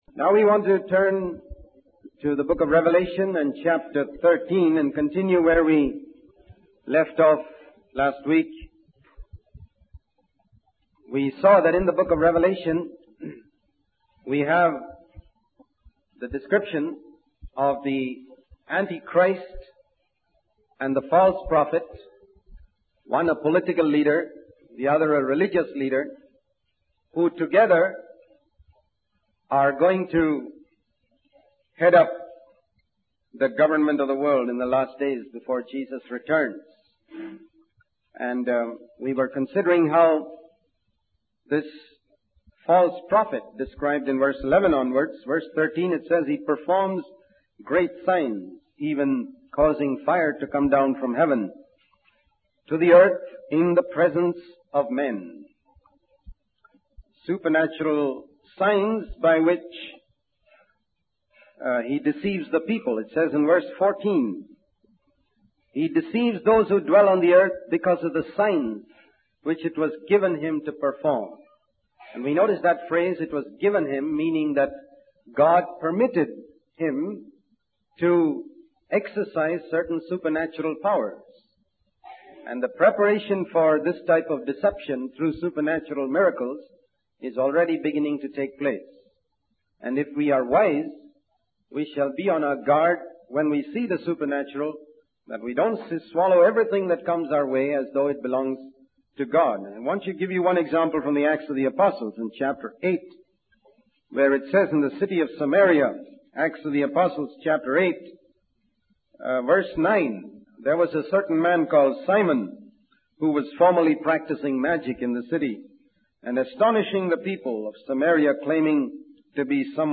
In this sermon, the speaker discusses the danger of worshiping images and idols, particularly in the form of television and video. He emphasizes that many believers spend hours in front of screens, prioritizing them over prayer and studying the Word of God. The speaker warns against following false prophets who perform supernatural miracles but teach things contrary to the Bible.